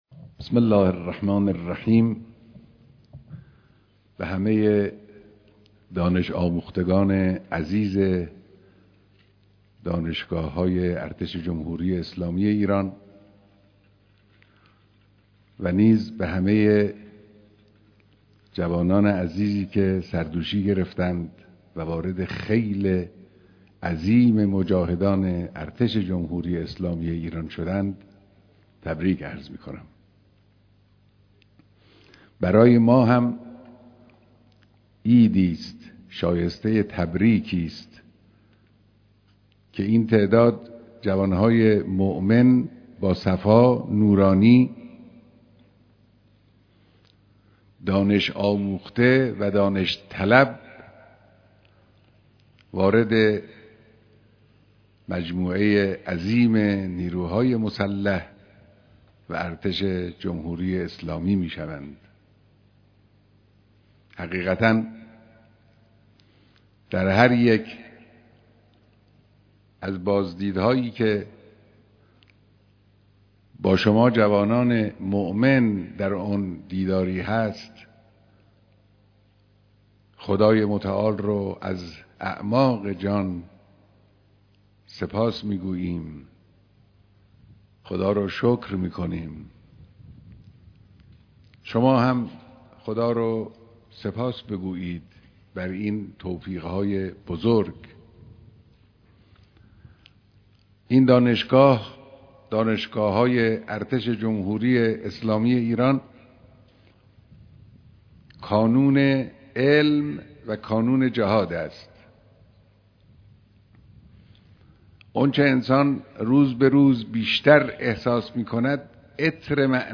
مراسم دانش آموختگی دانشجویان دانشگاههای افسری ارتش
بيانات در دانشگاه افسرى امام على (عليه‌السّلام)